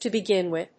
アクセントto begín wìth